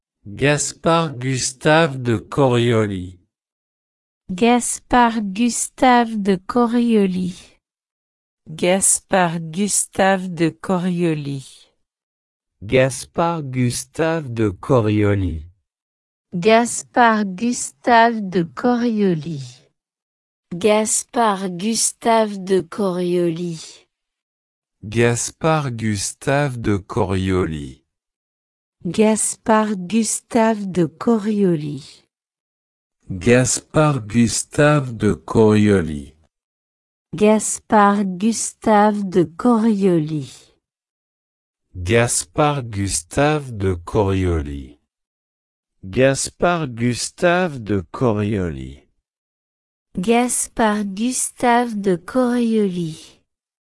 Pronunciation_Gaspard_Gustave_de_Coriolis.ogg